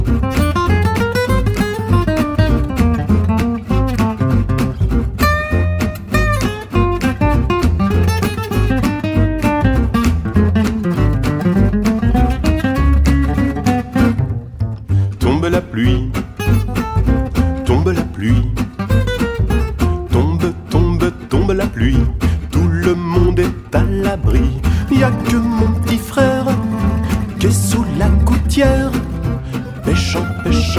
cinq musiciens de jazz manouche.